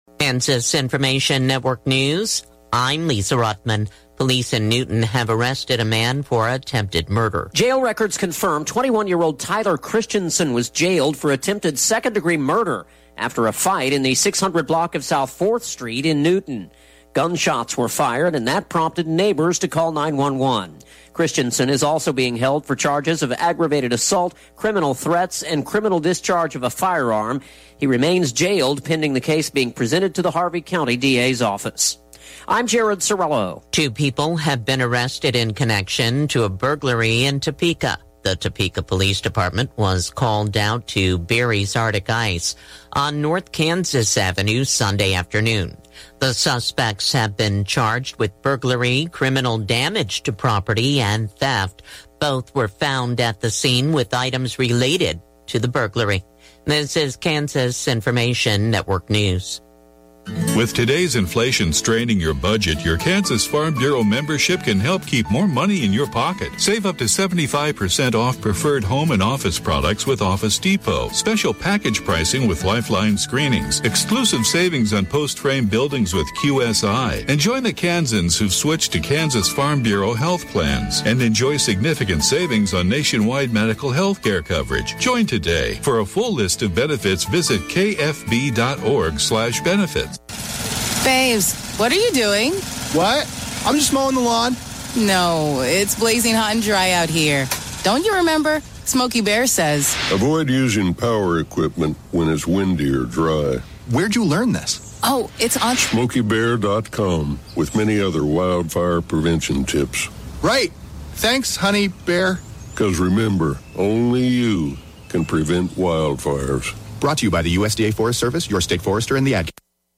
Classic Hits KQNK News, Weather & Sports Update – 10/2/2023